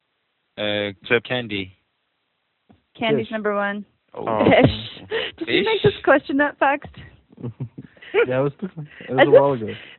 The following files are based on a four-person conversation (three male, one female) recorded over a PC-based conferencing test bed.
These files show the difference in speech quality between a conventional VoIP conference bridge using G.729A, and a Tandem-Free Operation conference with two selected speakers.
Tandem-Free Operation conference bridge using G.729A, with 2 selected talkers
SelandFwd-2of4-G729A.wav